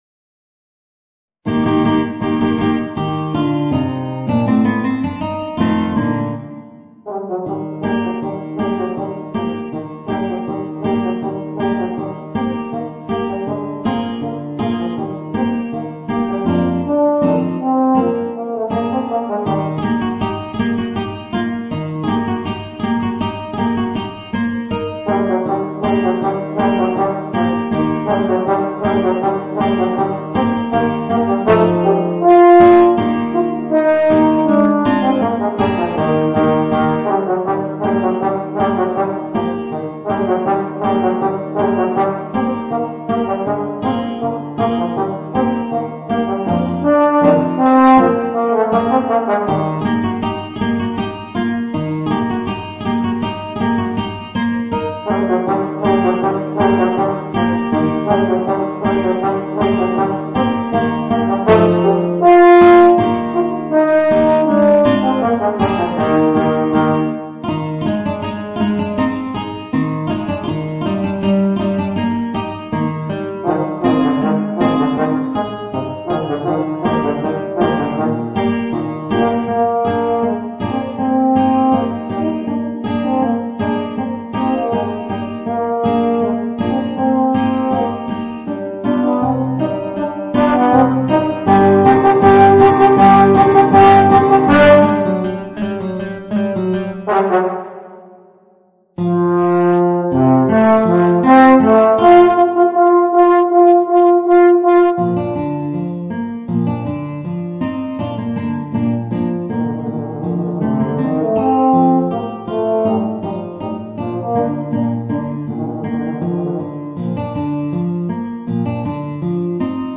Voicing: Guitar and Euphonium